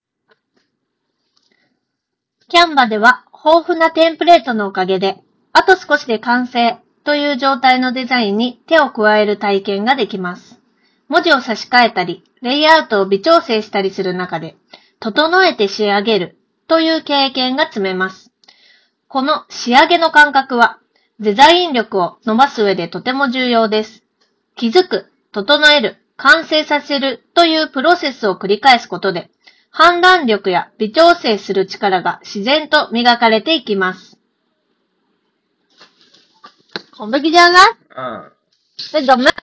▼SOUNDPEATS Space Proで収録した音声
iPhone 16 Proで撮影した動画と、イヤホン内蔵マイクで収録した音声を聴き比べてみると、内蔵マイクでは周囲の環境ノイズ（空調音、本に触れる音）などが効果的に低減されており、装着者の声のみを明瞭に拾い上げることができています。
さすがに専用のハイエンドマイクと比べると録音品質は劣るものの、普通に通話するには十分に優れた性能となっています。特に装着者の発言内容が強調されており、聴き取りやすいです。